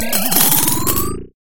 Звук подъема монет в игре (приумножение очков) (00:02)